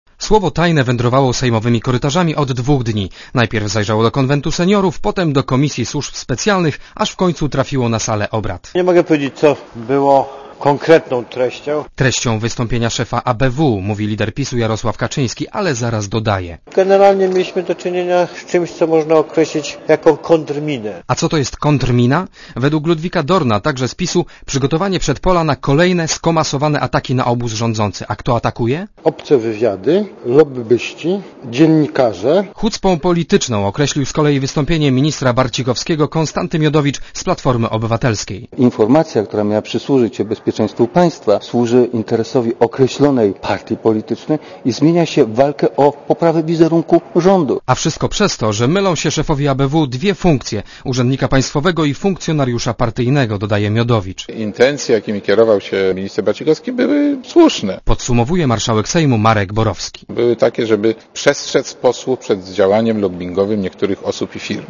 Komentarz audio (252Kb)